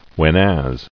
[when·as]